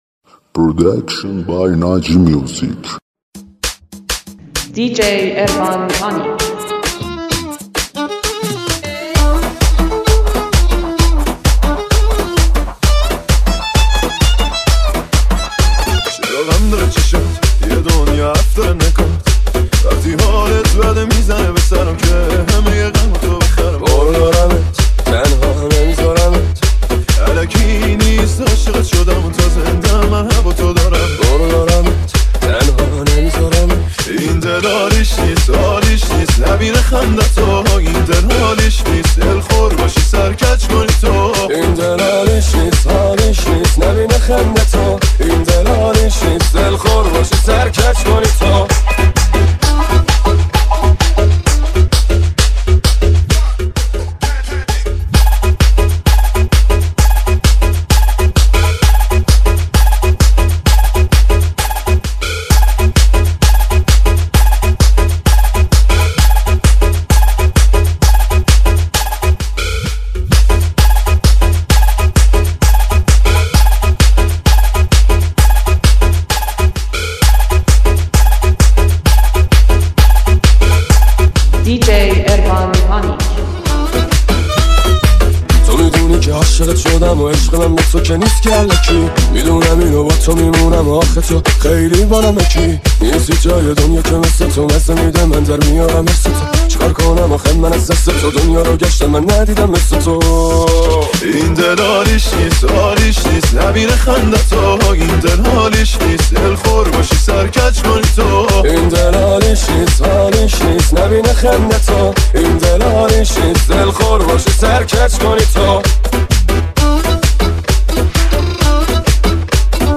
ریمیکس شاد جدید